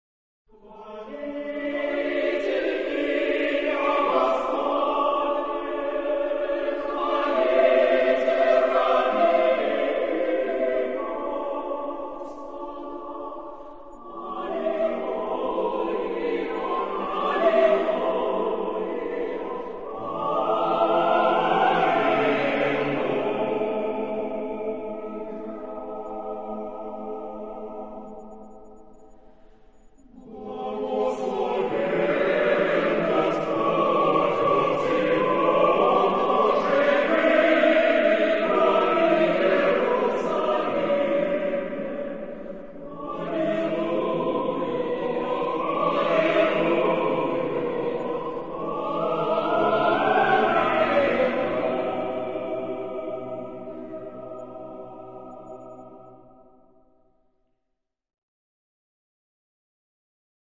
: Ka015 Form der Ausgabe: Chorpartitur mit unterlegtem Klavierauszug für Probezwecke Beschreibung Sprache: kirchenslawisch + Bearbeitung in Transliteration Zeitepoche: 19. Jh. Genre-Stil-Form: geistlich ; liturgische Hymne (orthodox) ; orthodox
SSAATTBB (8 gemischter Chor Stimmen )